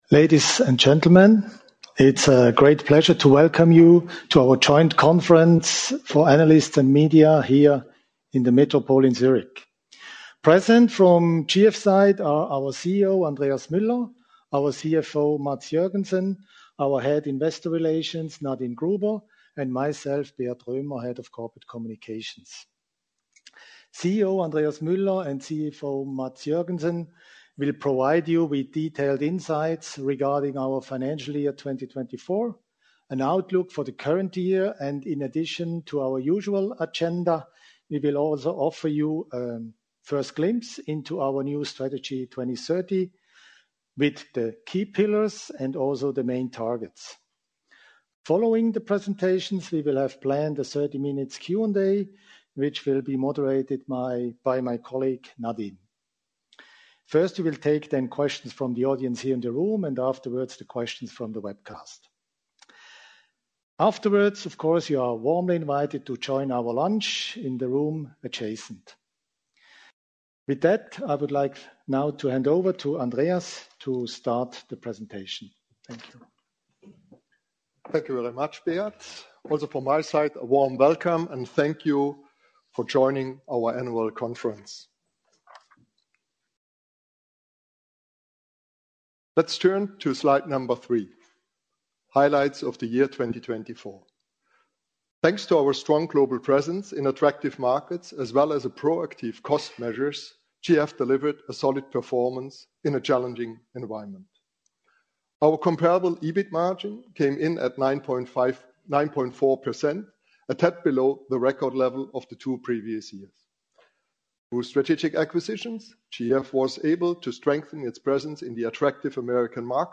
audio-webcast-analyst-and-media-conference-2025-en.mp3